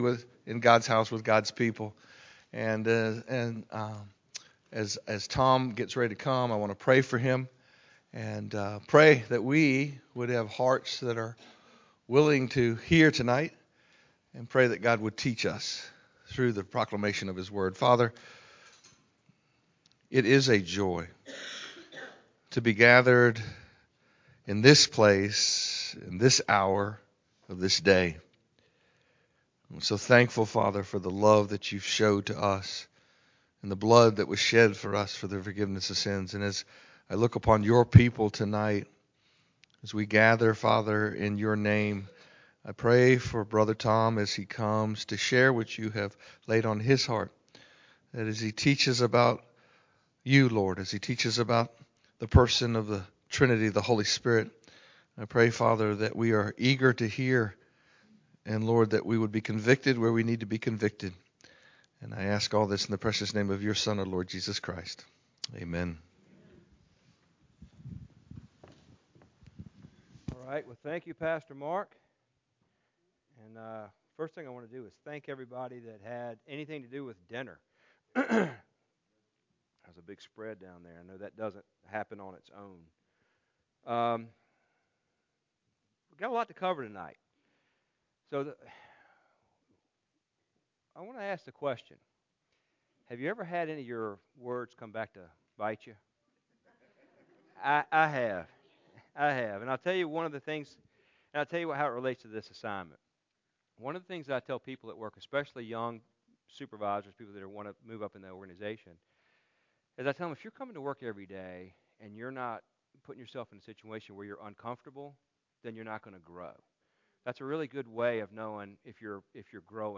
Sunday Night Teaching